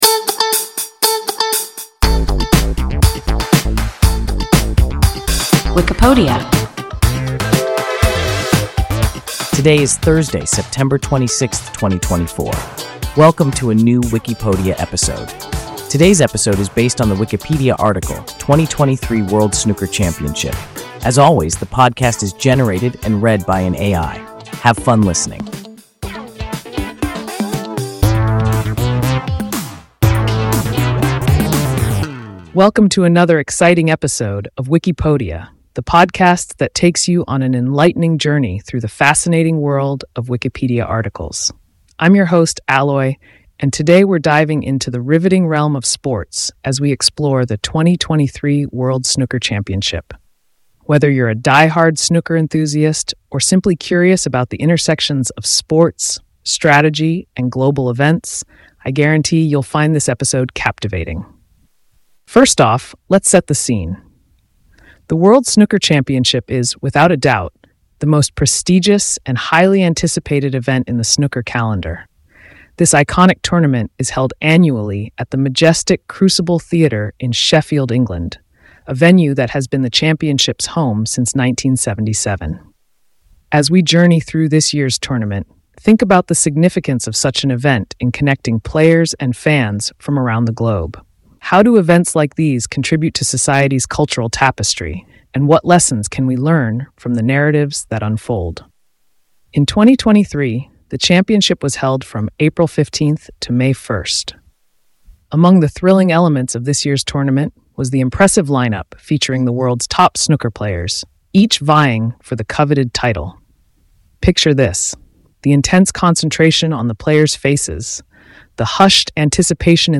2023 World Snooker Championship – WIKIPODIA – ein KI Podcast